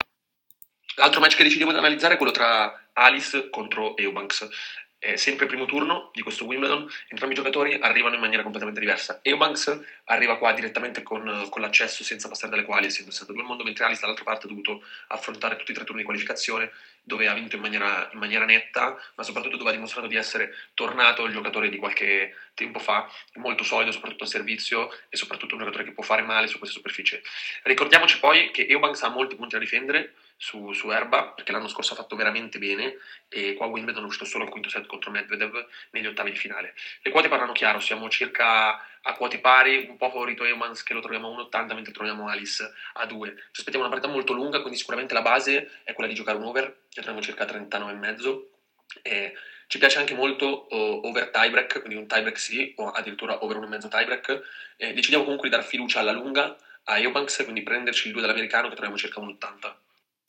In questa audio analisi il nostro esperto di Tennis